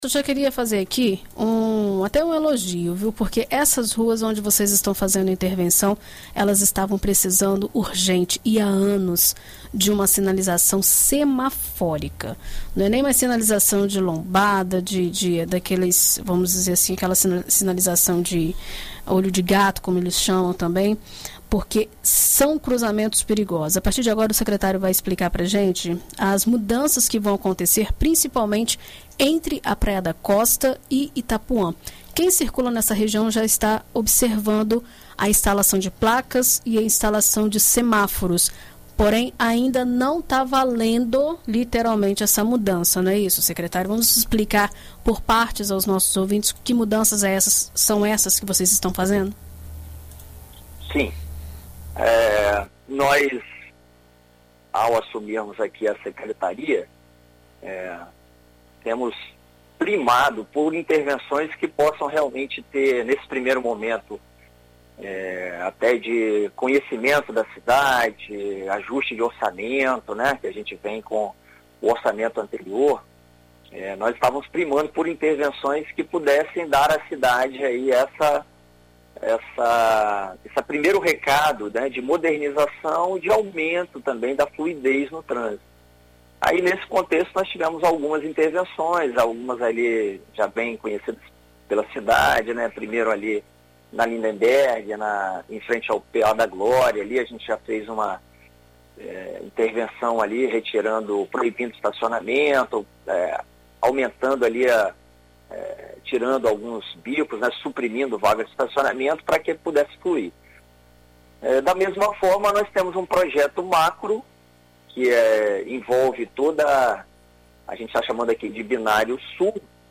Em entrevista à BandNews FM Espírito Santo nesta terça-feira (14), o secretário de Defesa Social e Trânsito do município, tenente-coronel Geovanio Silva Ribeiro, detalha as ações que serão feitas no trânsito e explica a motivação.